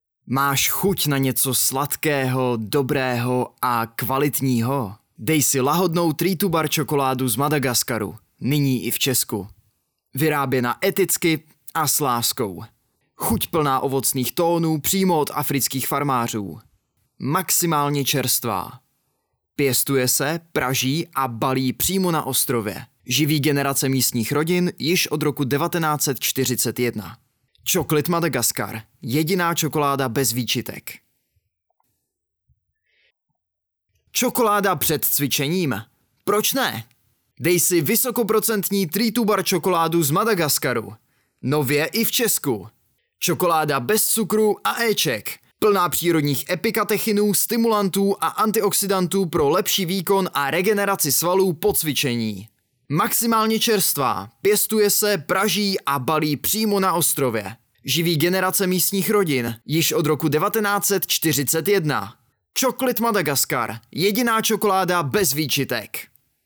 K dispozici mám profesionální mikrofon a zvuk jsem schopen sám upravit a poté odeslat v nejvyšší možné kvalitě.
Hlas pro Vaše video! (voiceover)